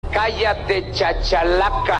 Play, download and share chachalaca original sound button!!!!
chachalaca.mp3